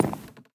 Minecraft Version Minecraft Version 1.21.5 Latest Release | Latest Snapshot 1.21.5 / assets / minecraft / sounds / block / hanging_sign / step4.ogg Compare With Compare With Latest Release | Latest Snapshot
step4.ogg